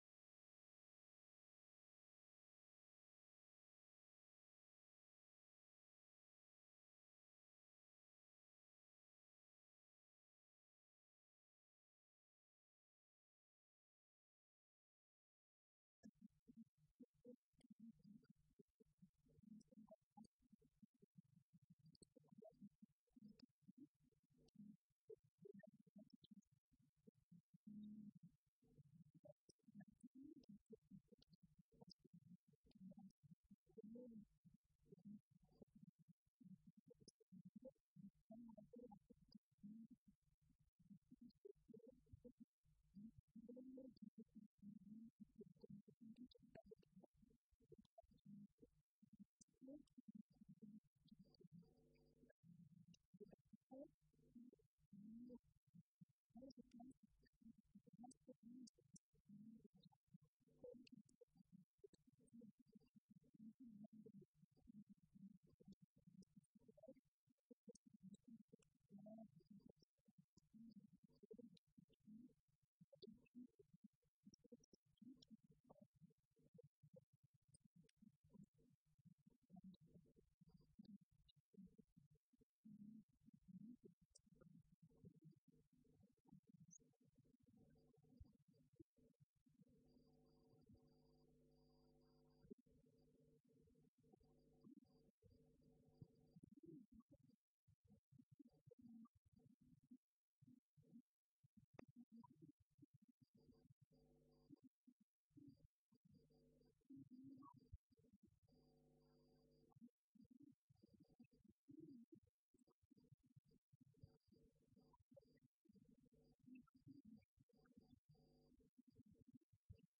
Session L’historien, la demande sociale et la diffusion des savoirs. Colloque L’Islam et l’Occident à l’époque médiévale.